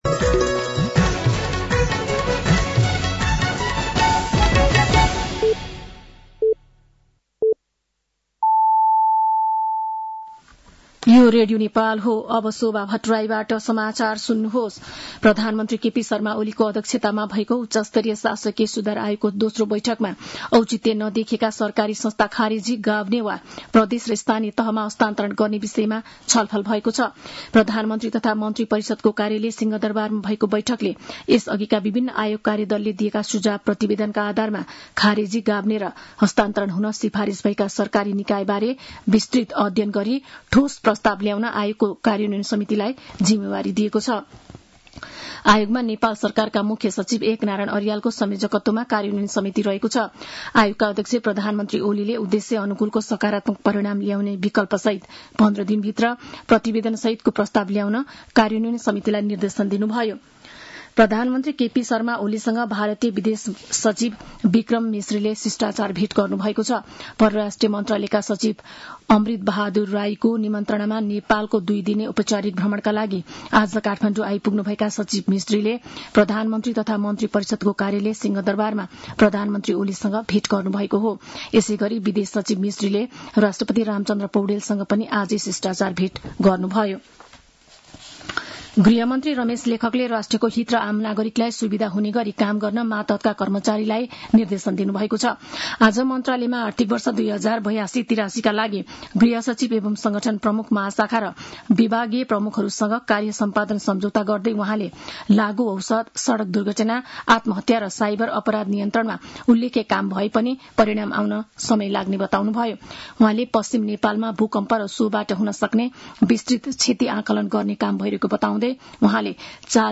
साँझ ५ बजेको नेपाली समाचार : १ भदौ , २०८२
5.-pm-nepali-news-1-6.mp3